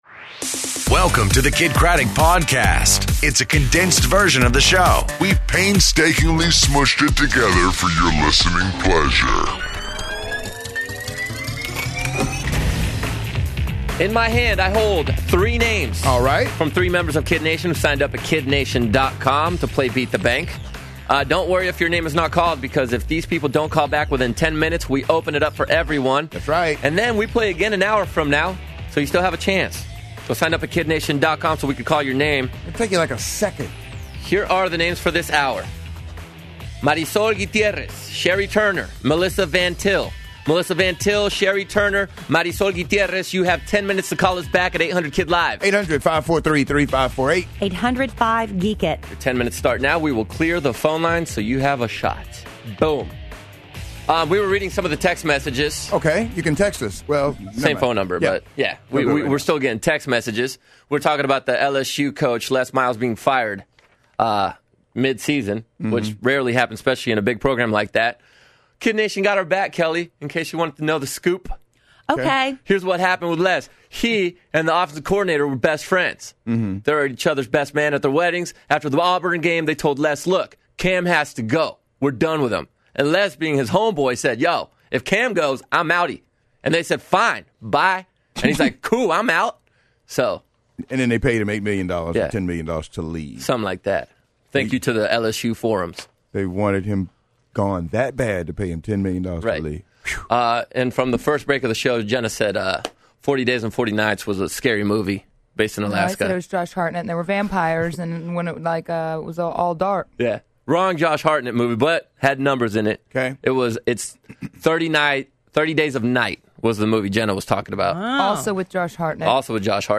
Sadie Robertson In Studio